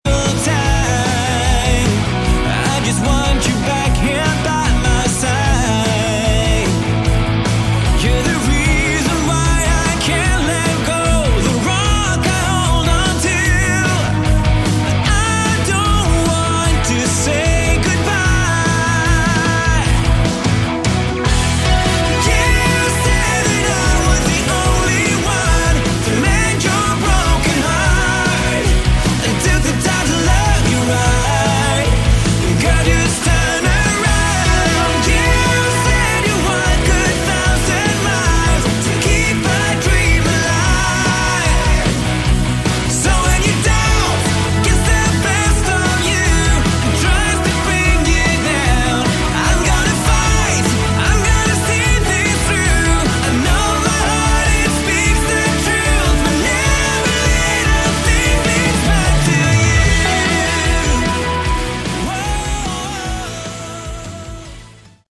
Category: AOR
guitar, vocals, keyboards
bass
drums